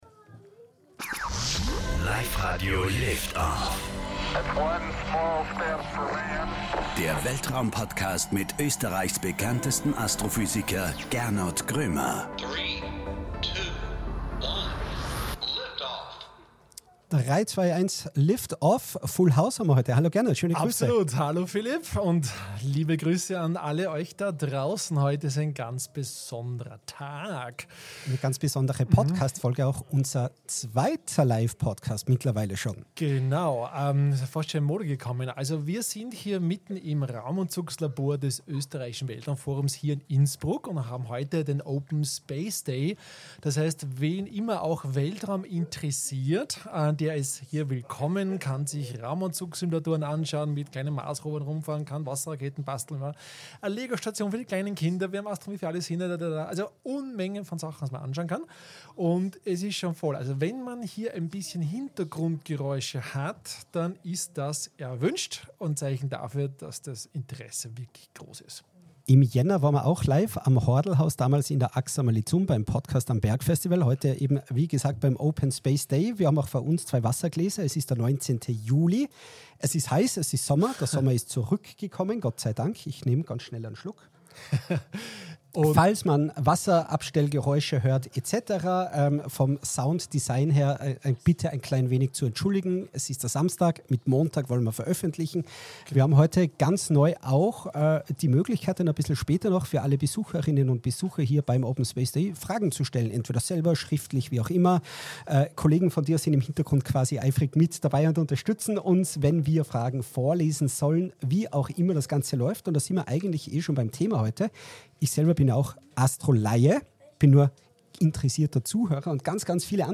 Diese Folge ist beim Open Space Day des ÖWF am 19.7.2025 live vor Publikum aufgezeichnet worden.
Ach ja... und es wird auch gesungen :) Unbedingt reinhören!